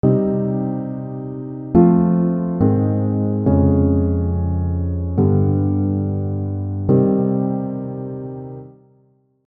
The chord progression is: Cm - F - Ab - Db - G - Cm.
Cm - F - Ab - Db - G - Cm